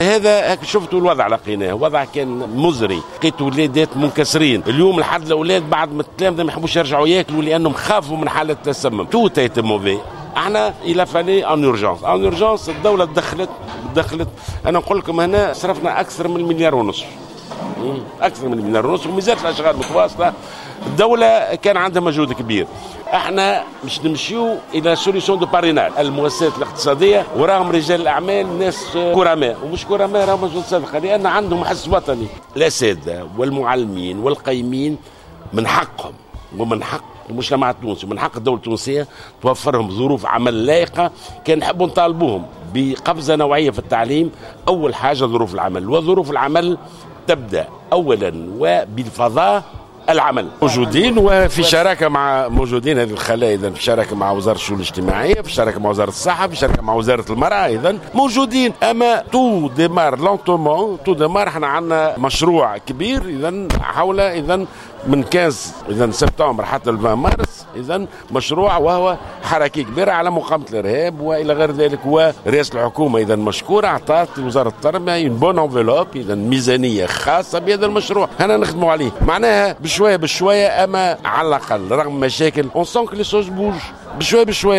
وأكد في تصريحات صحفية أنه يعول على الحس الوطني لرجال الأعمال الذي لا يشك فيه، بحسب تعبيره. وجاءت تصريحات جلول على هامش زيارة أدّاها اليوم إلى المدرسة الإعدادية طريق تونس بوسالم من ولاية جندوبة أعلن خلالها أنّ الدولة أنفقت أكثر من 1.5 مليون دينار في إطار خطة عاجلة لإصلاح هذه المدرسة.